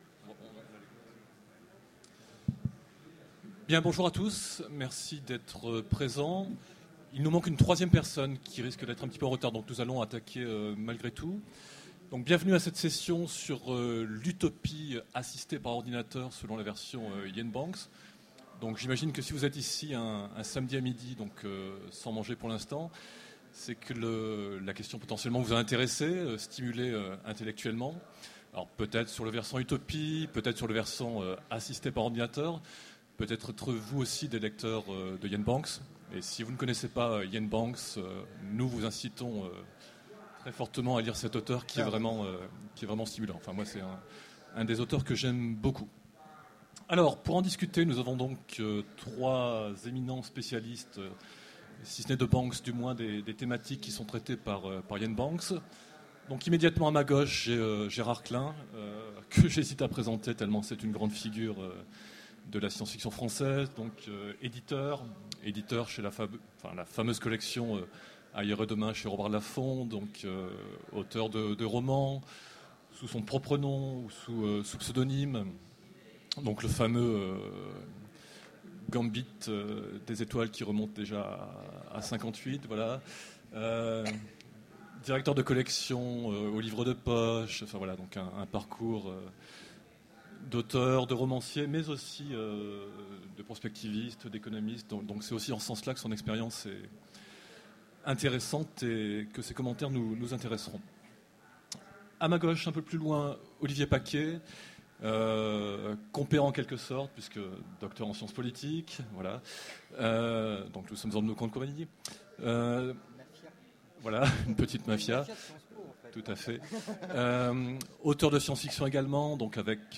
Mots-clés Utopie Conférence Partager cet article